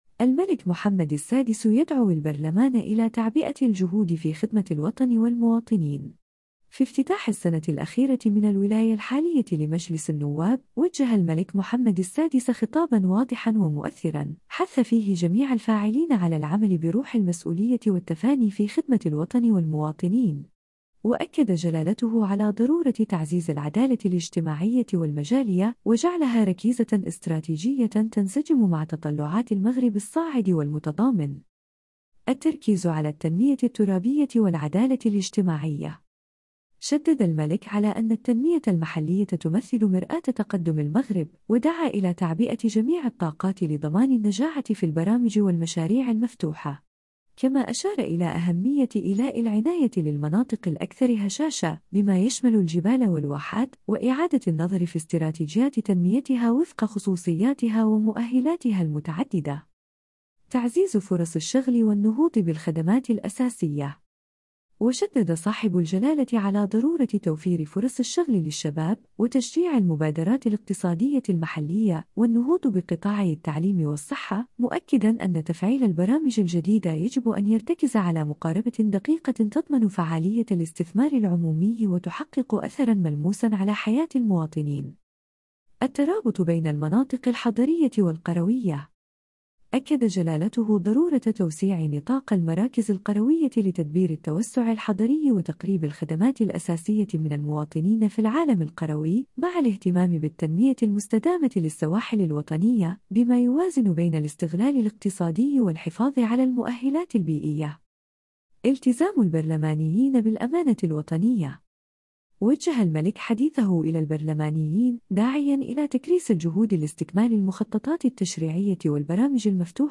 خطاب الملك محمد السادس أمام البرلمان 2025